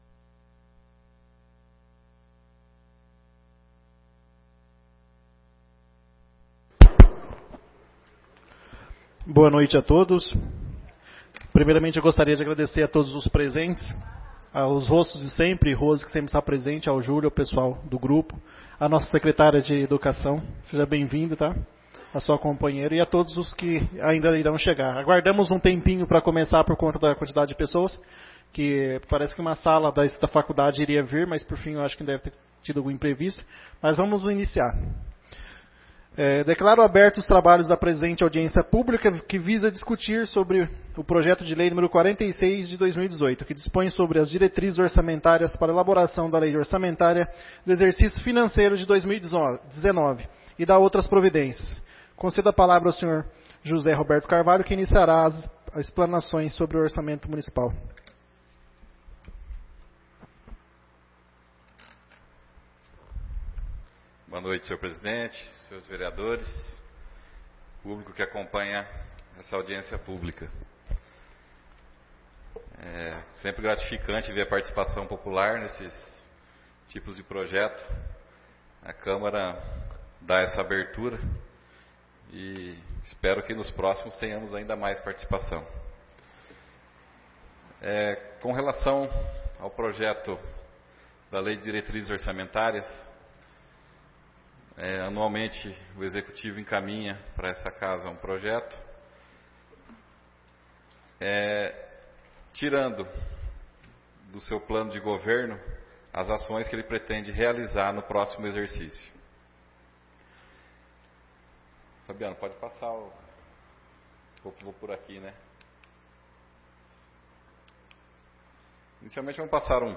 16/05/2018 - Audiência Pública LDO 2019